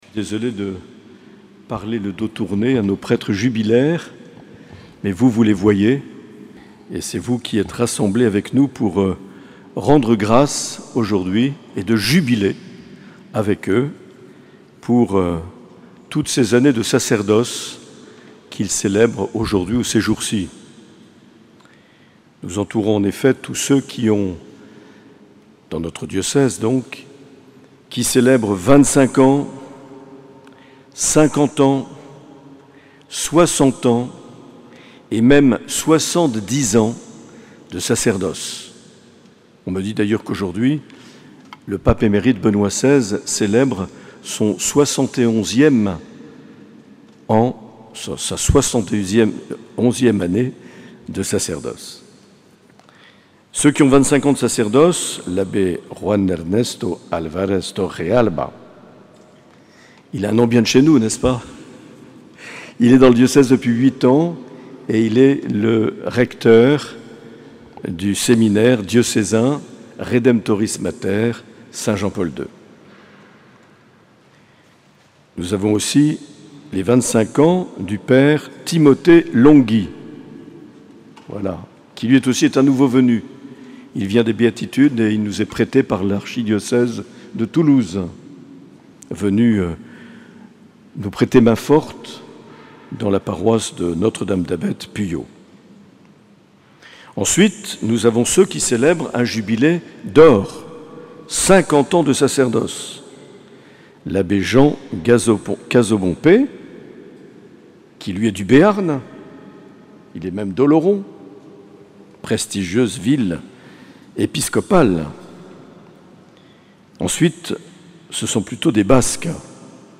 29 juin 2022 - Cathédrale de Bayonne - Messe avec les prêtres jubilaires